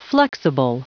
Prononciation du mot flexible en anglais (fichier audio)
Prononciation du mot : flexible